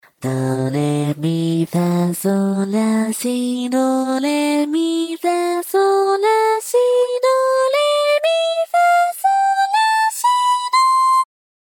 四音階通常音源
收錄音階：A3 D4 G4 B4